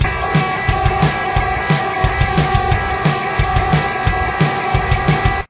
This riff is pretty standard and is in drop D tuning.
Guitar 1